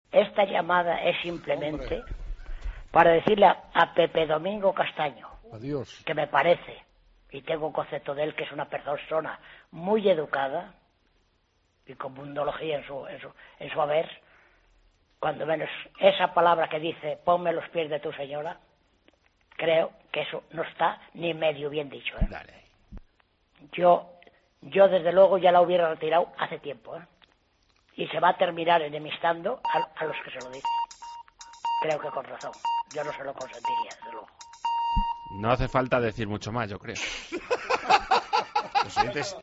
El oyente enfurecido